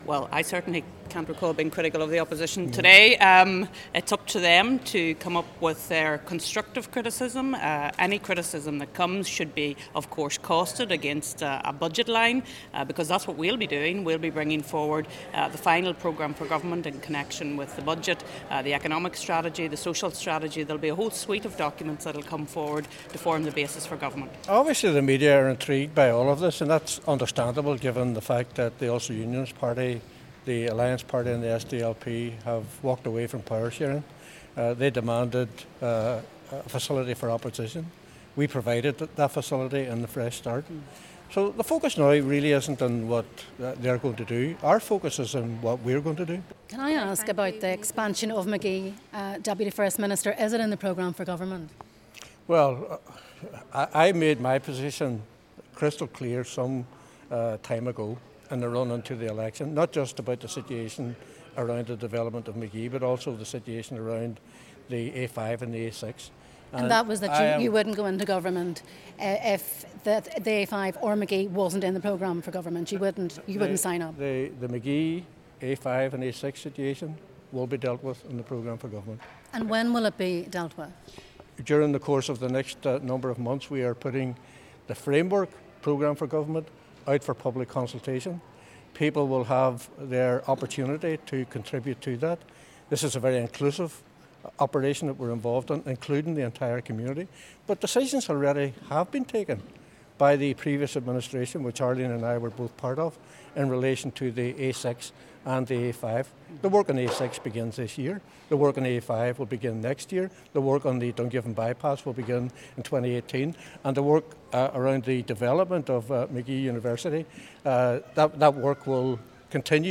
The First and Deputy First Ministers say they're committed to the expansion of Magee and the A5 as part of the programme for government. Martin McGuinness and Arlene Foster where attending the tourism awards in the city last night. When asked both ministers denied that they were at loggerheads with the opposition.